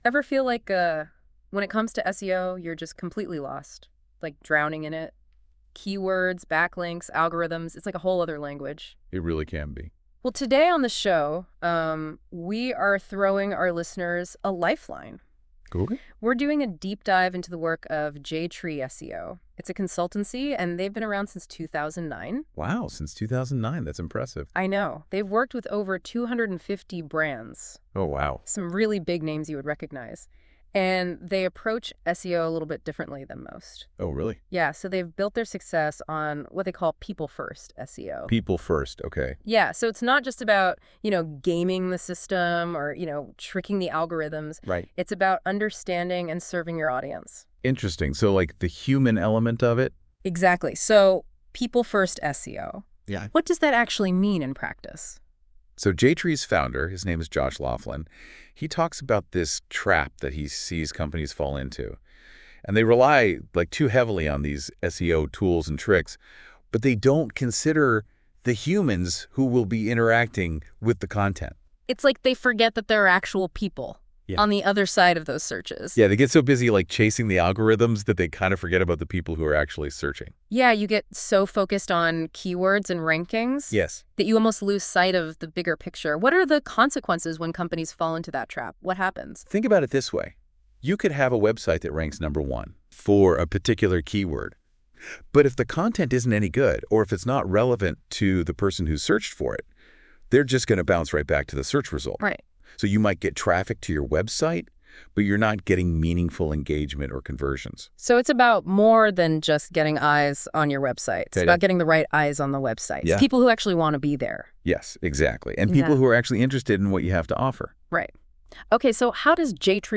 You can listen to the two AI podcast hosts below.
NotebookLM will process your content and generate a conversation between two AI hosts.